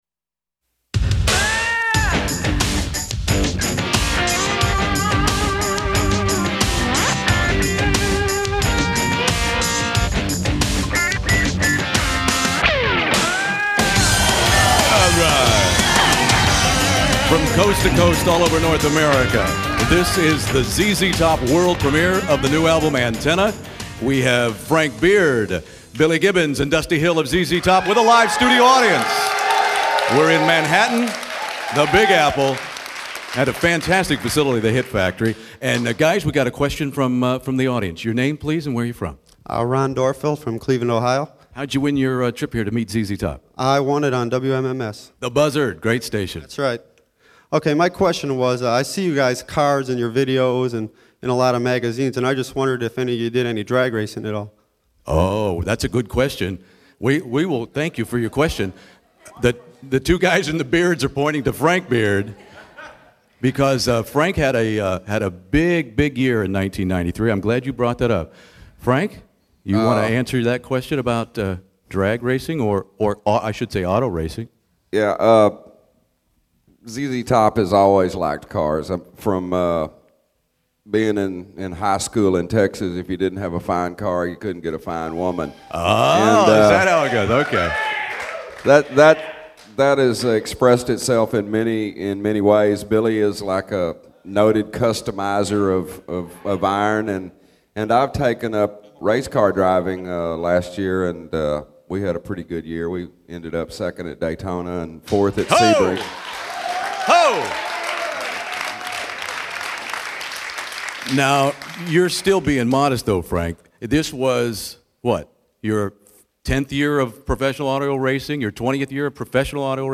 This is the conclusion of the ZZ Top Antenna world premiere radio broadcast on January 15, 1994 with Billy Gibbons, Dusty Hill, and Frank Beard live from Manhattan’s Hit Factory to millions all over North America. In part two we learn that ZZ Top drummer Frank Beard had a fascinating side hustle as the owner of his own professional auto racing team, which came in second at Daytona in their first race!